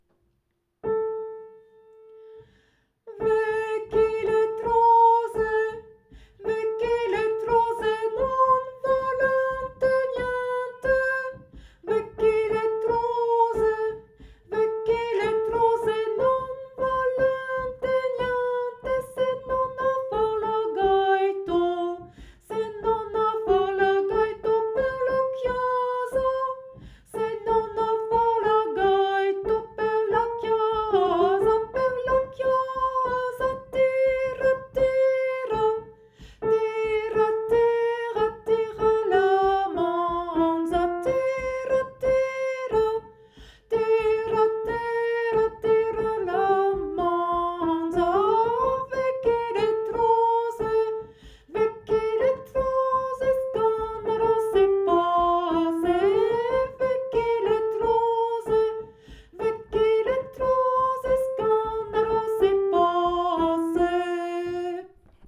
Soprano travail du soupir 3° ligne au soupir 4° ligne sans les doubles croches